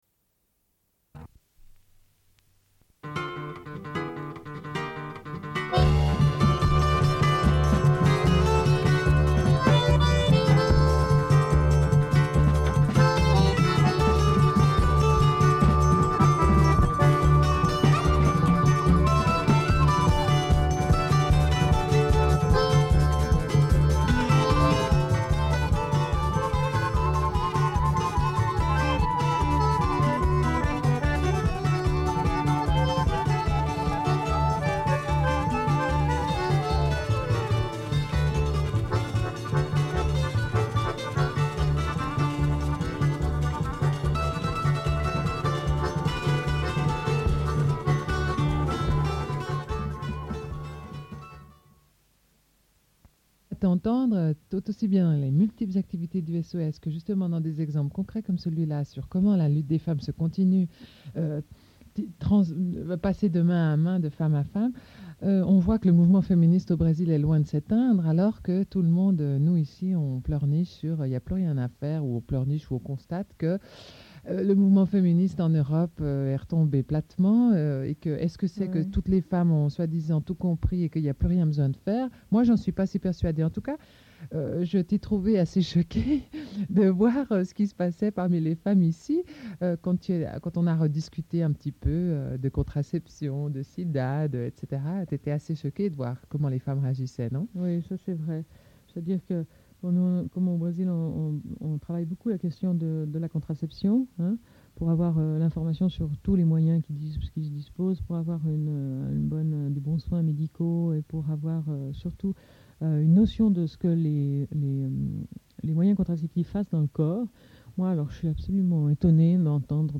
Une cassette audio, face A00:31:51
Entretien avec quatre membres.